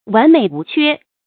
wán měi wú quē
完美无缺发音